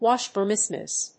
washbasins.mp3